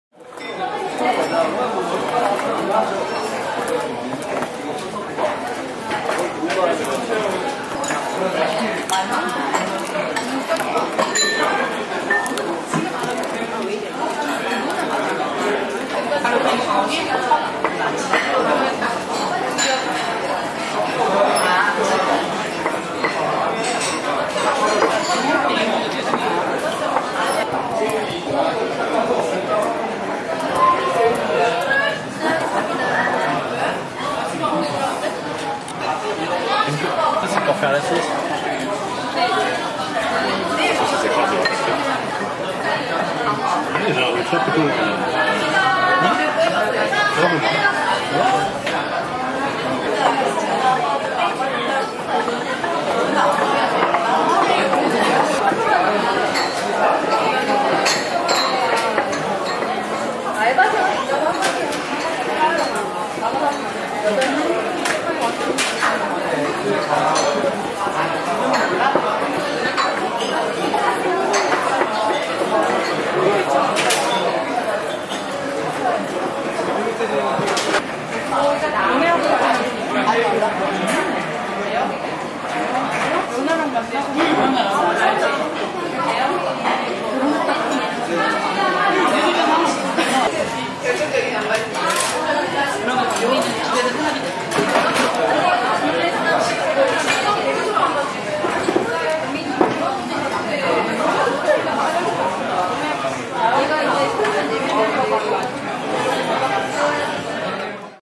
Dinner Party Ambiance